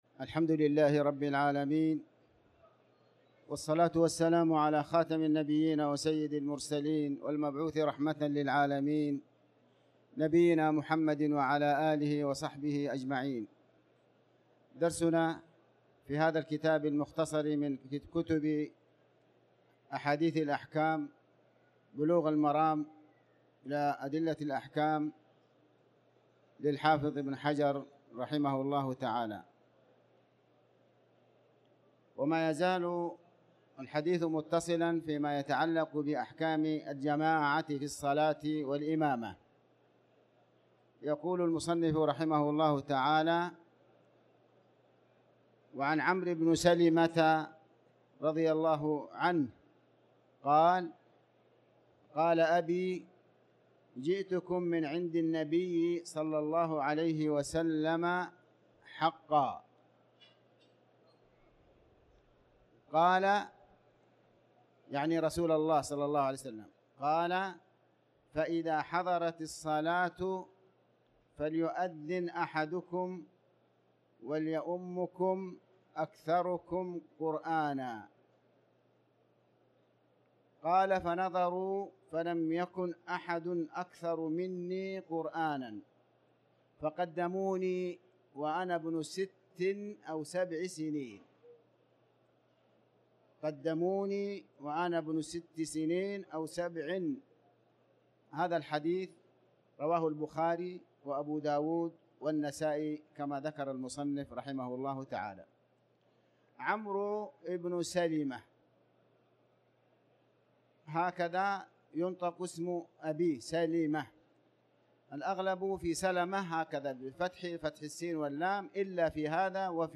تاريخ النشر ٢٨ رجب ١٤٤٠ هـ المكان: المسجد الحرام الشيخ